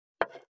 593魚切る,肉切りナイフ,まな板の上,
効果音厨房/台所/レストラン/kitchen食器食材